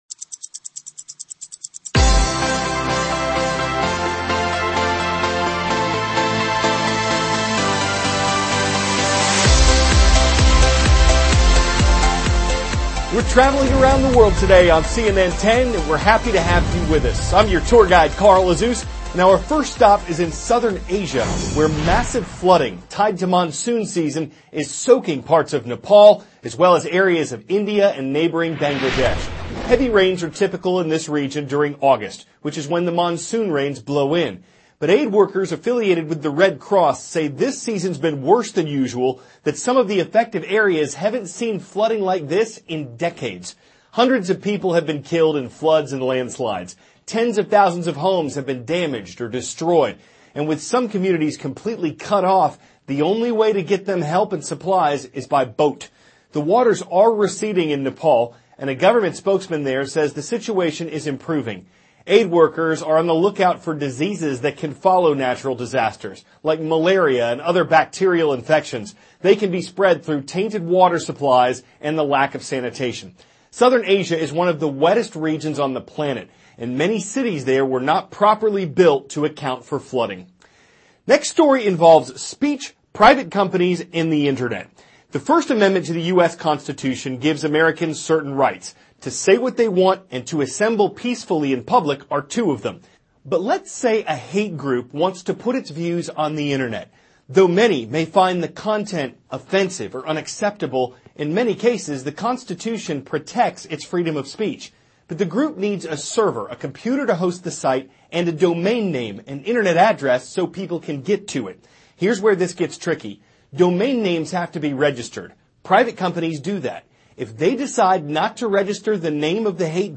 CARL AZUZ, cnn 10 ANCHOR: We`re traveling around the world today on cnn 10 and we`re happy to have you with us.